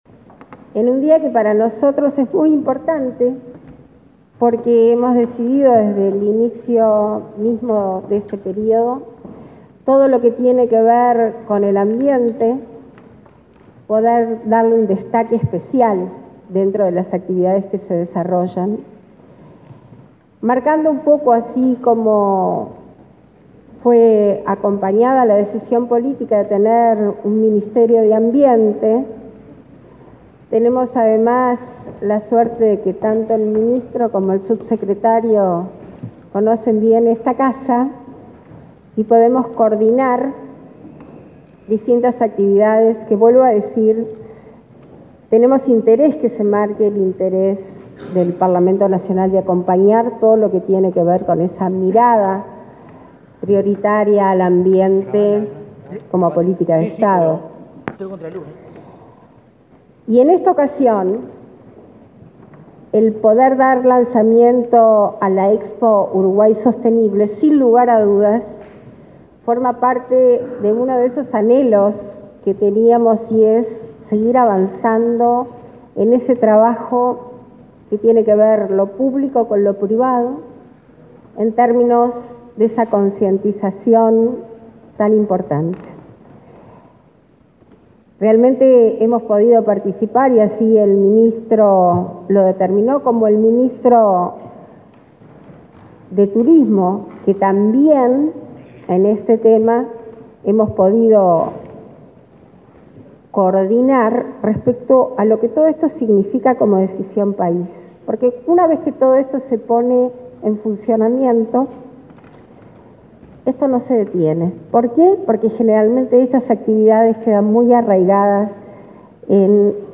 Palabras de autoridades en el lanzamiento de la Expo Uruguay Sostenible
Palabras de autoridades en el lanzamiento de la Expo Uruguay Sostenible 25/05/2022 Compartir Facebook X Copiar enlace WhatsApp LinkedIn Este miércoles 25 en el Palacio Legislativo, se efectuó el lanzamiento de la Primera Expo Uruguay Sostenible, que se realizará entre el viernes 10 y el domingo 12 de junio en el velódromo municipal de Montevideo. La vicepresidenta de la República, Beatriz Argimón; el ministro de Turismo, Tabaré Viera, y su par de Ambiente, Adrián Peña, señalaron la importancia de la exposición.